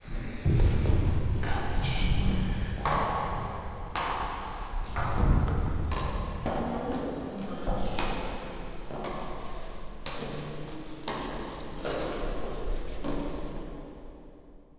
shagi.mp3